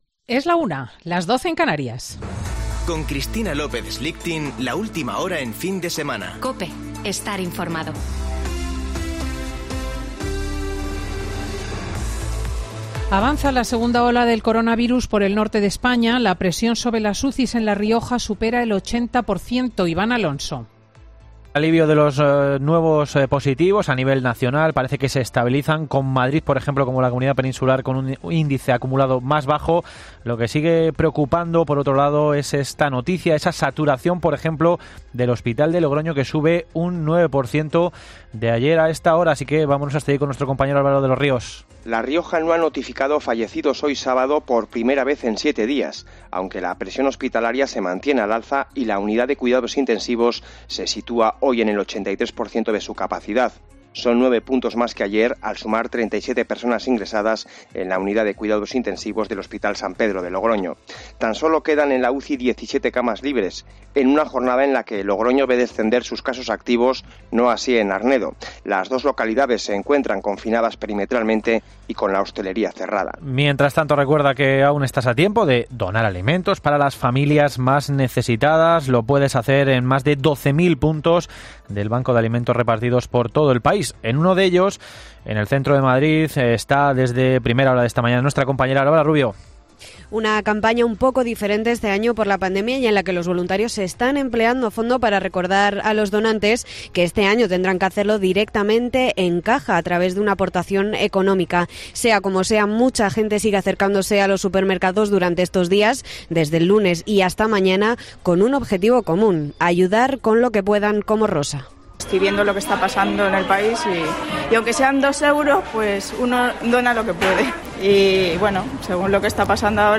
Boletín de noticias de COPE del 21 de noviembre de 2020 a las 13.00 horas